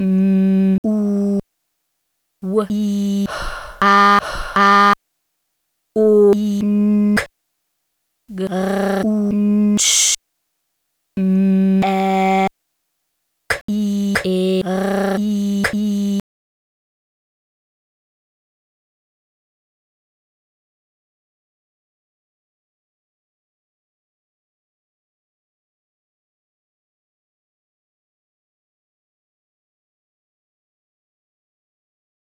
Das beginnt schon lautgemalt lustig. Und dann dieses explizite, sorgfältig ausgearbeitete Scheitern an diesen fremden Sprachen der Tiere.